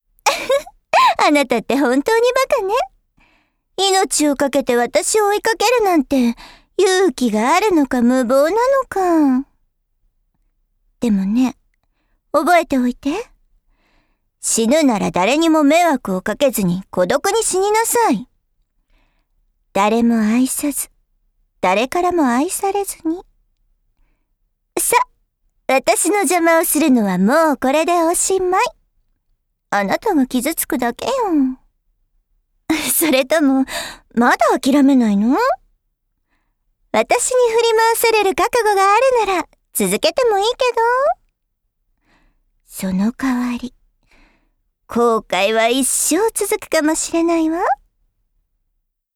お姉さんキャラクターは、落ち着いた低めのトーンで話すことが多く、このトーンは成熟した女性らしさと優雅さを表現するのに効果的です。
【お姉さんボイスのコツを意識してセリフを読む】